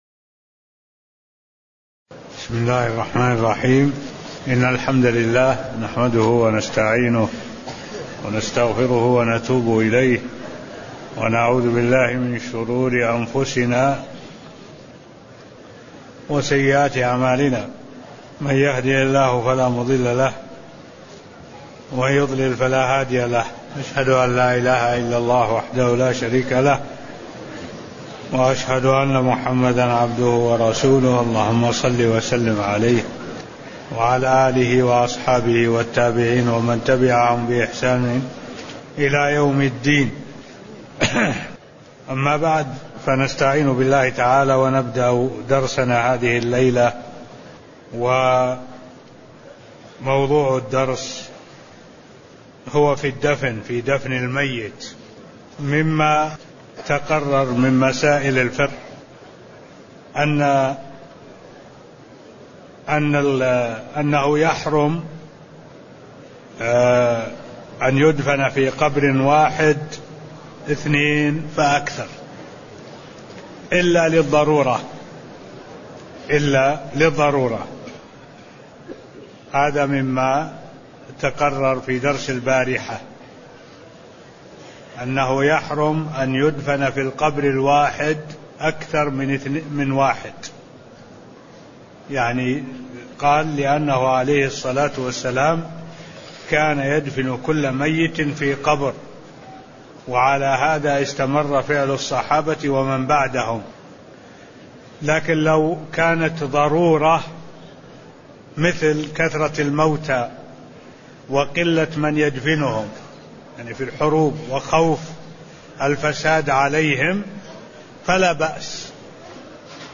تاريخ النشر ٢٤ ذو الحجة ١٤٢٦ هـ المكان: المسجد النبوي الشيخ: معالي الشيخ الدكتور صالح بن عبد الله العبود معالي الشيخ الدكتور صالح بن عبد الله العبود صفة الدفن (005)